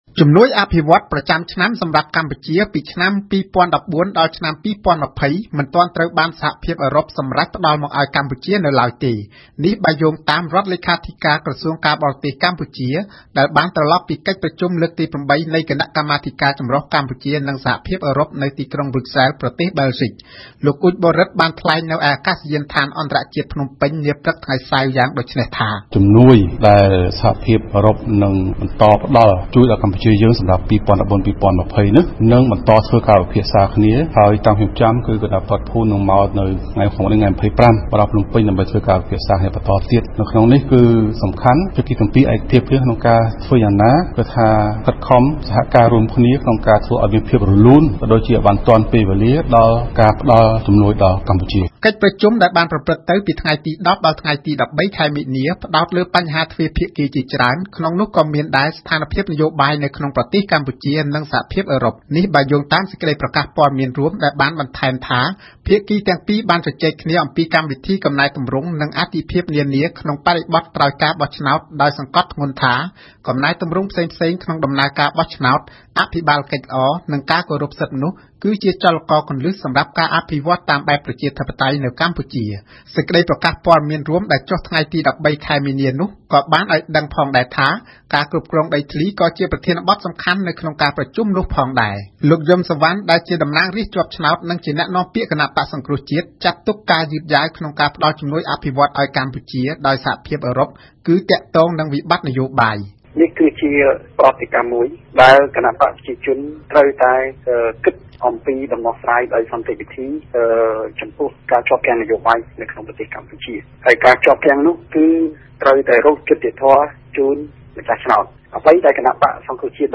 សេចក្តីរាយការណ៍